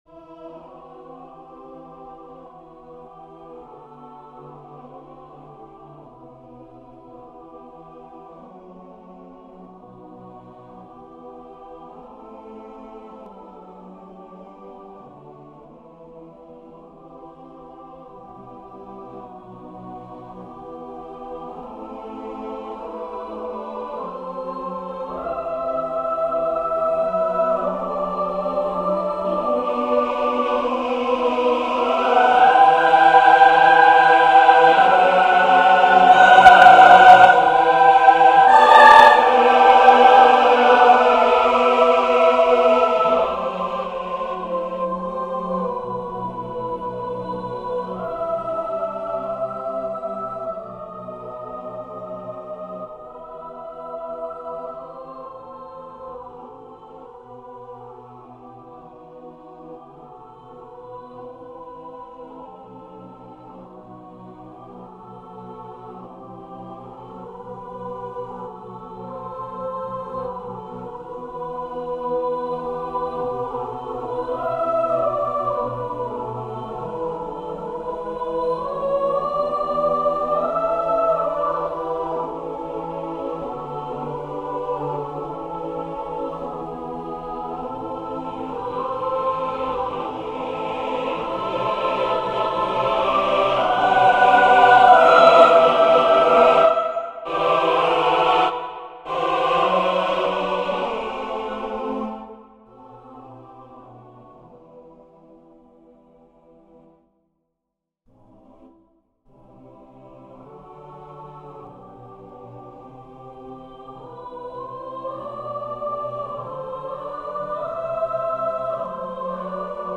Echo (For SATB) Updated 12/30 Feedback requested.